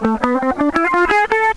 La gamme mineure harmonique est une gamme mineure dont on a haussé le septième degré d'un demi-ton.
Gamme Mineure Harmonique de La (cliquez pour écouter)